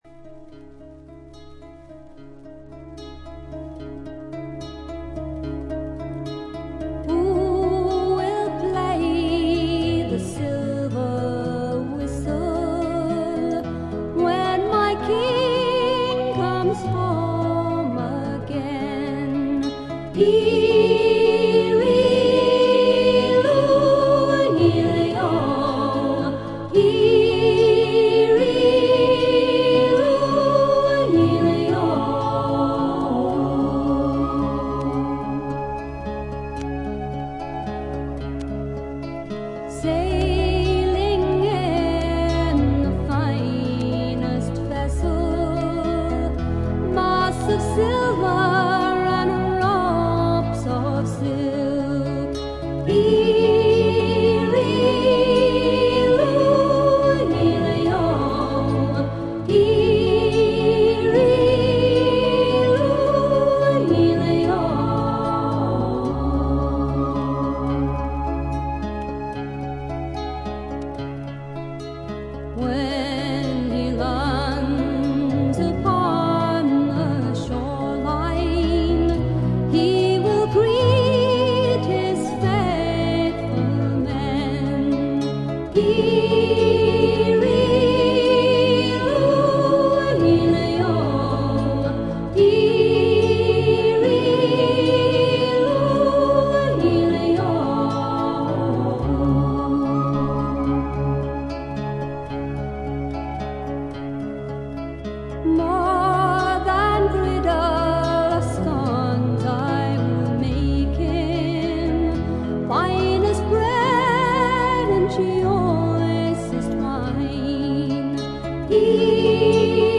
英国の男女フォーク・デュオ
しみじみとした情感が沁みてくる歌が多いです。